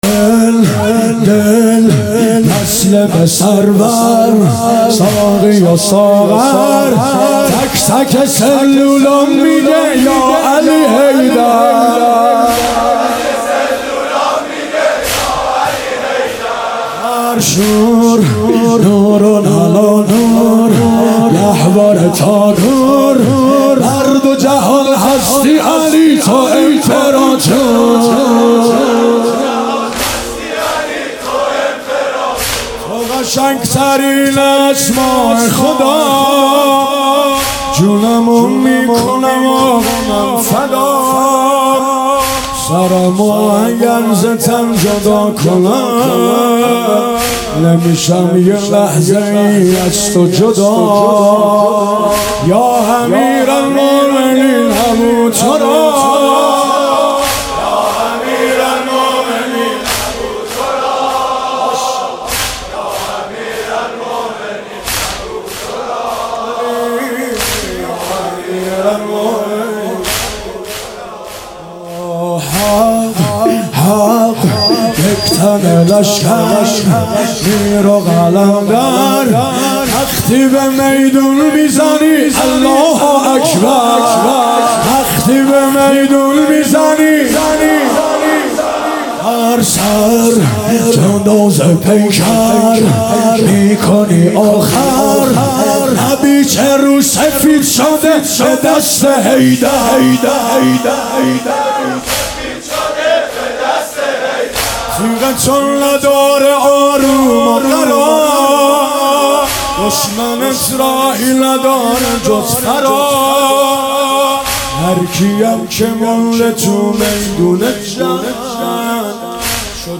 محرم 1399 | هیئت عشاق الرضا (ع) تهران